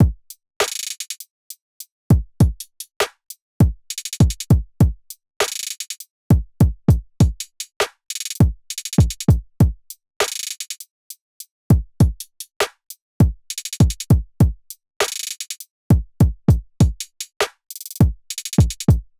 AV_Backflip_Drums_100bpm.wav